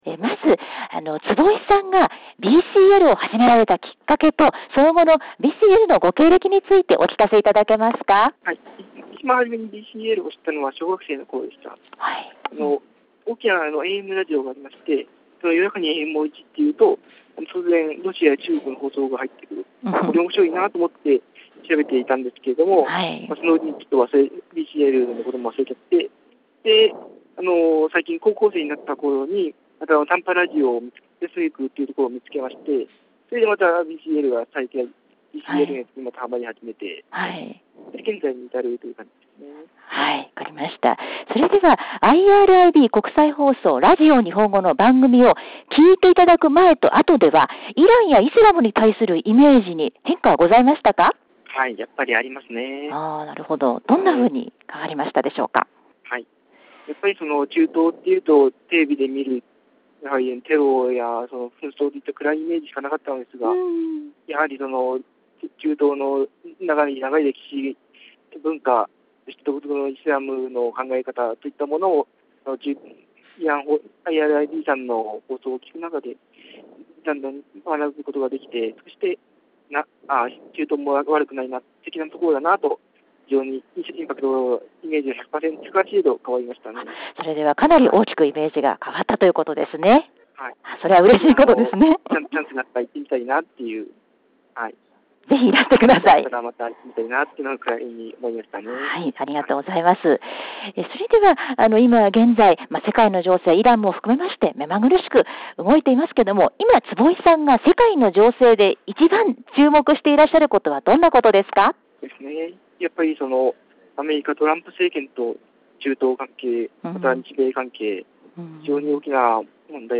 ペルシャ絨毯店「バハール」ギャッベ教室の皆さんへのインタビューの最後です。